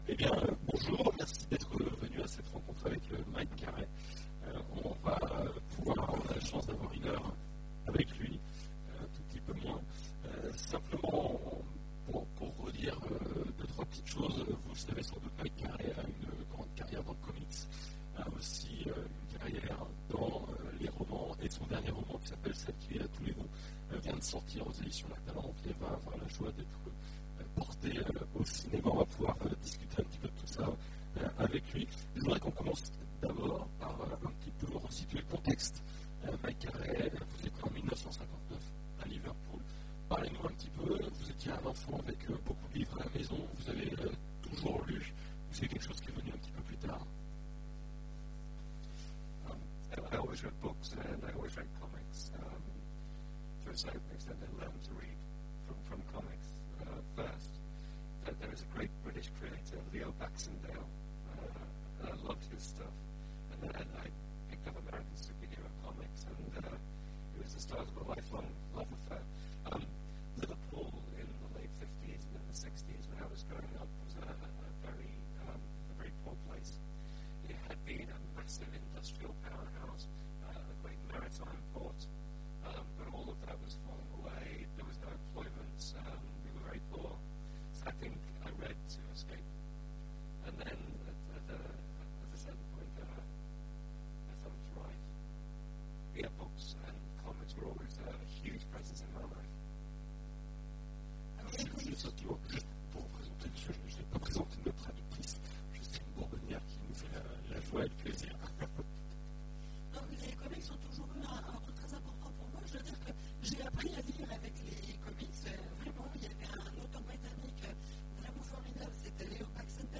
Imaginales 2015 : Entretien avec... M. R. Carey
M. R. Carey Télécharger le MP3 à lire aussi M.R. Carey Genres / Mots-clés Rencontre avec un auteur Conférence Partager cet article